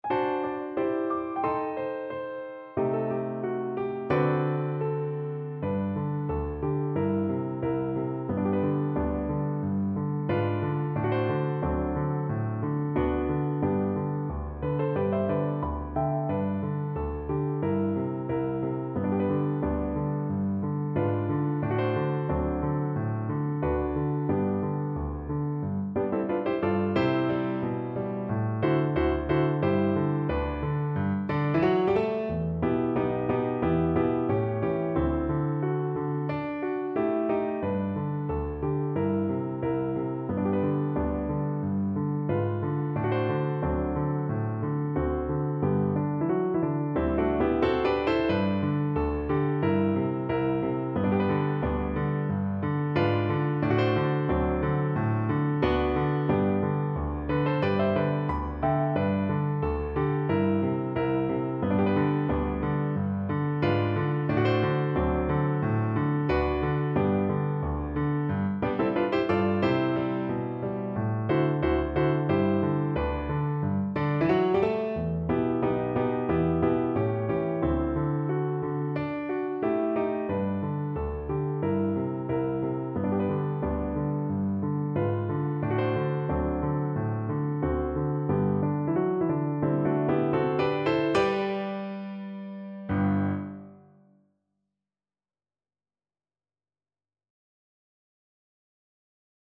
2/2 (View more 2/2 Music)
Grazioso =90
Classical (View more Classical Cello Music)